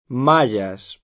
vai all'elenco alfabetico delle voci ingrandisci il carattere 100% rimpicciolisci il carattere stampa invia tramite posta elettronica codividi su Facebook maya [ m #L a ] etn.; inv. — sp. maya [ m #L a ]; pl. mayas [ m #L a S ]